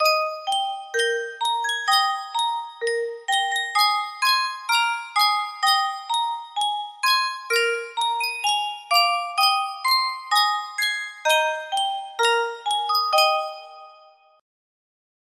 Sankyo Music Box - Old Black Joe DOG music box melody
Full range 60